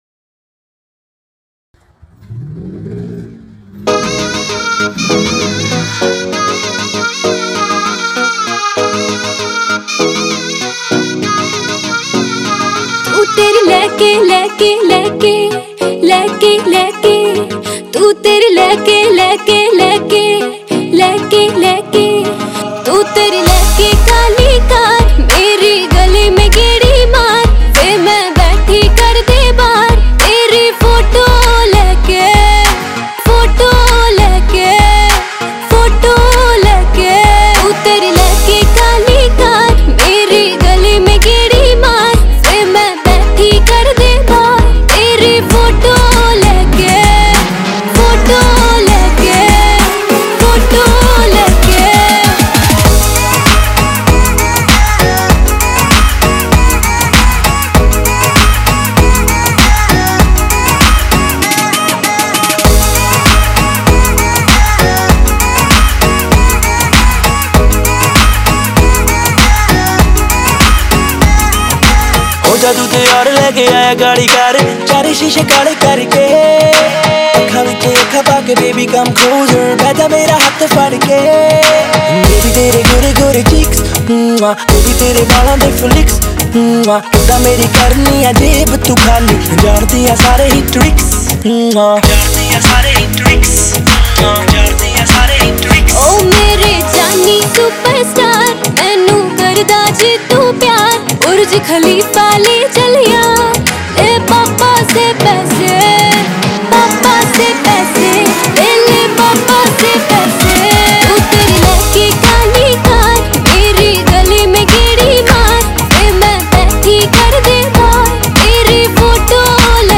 Pop Songs